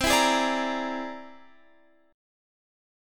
C Augmented 9th